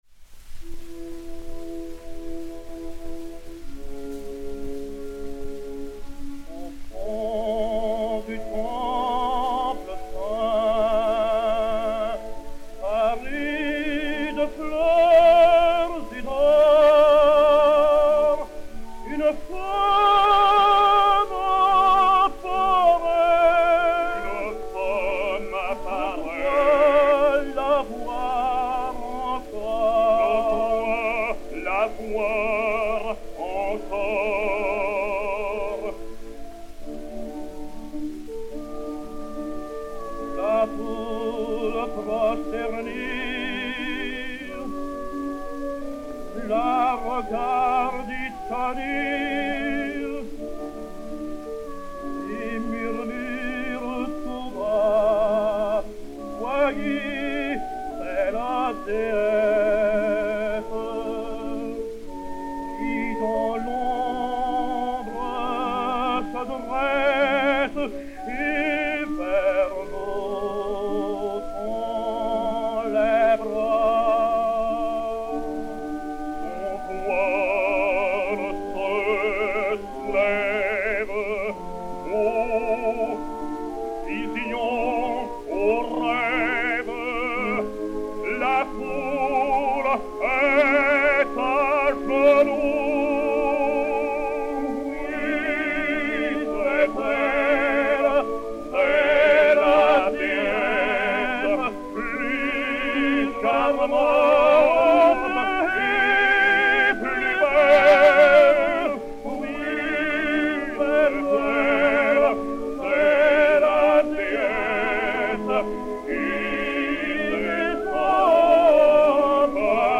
Disque Pour Gramophone 034203, mat. 02964v, enr. à Paris le 16 mai 1914